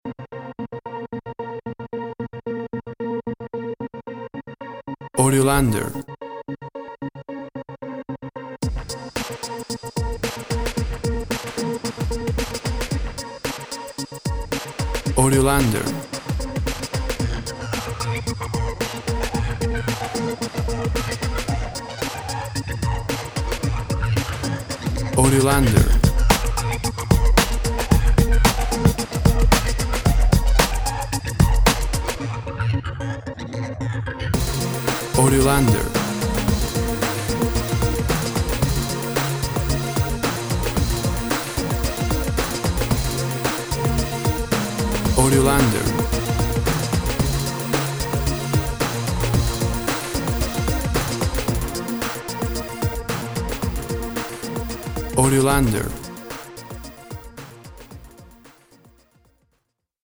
Full of rap synths and hip hop tunes!.
Tempo (BPM) 100